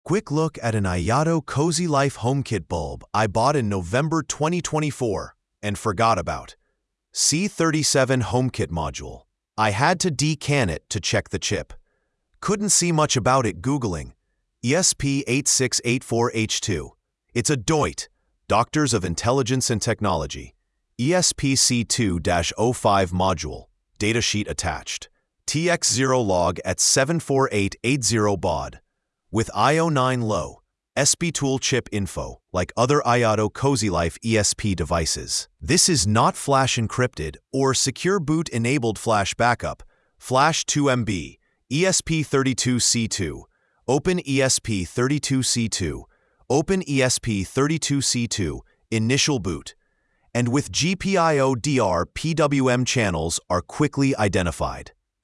📢 Listen (AI):
Generated by the language model.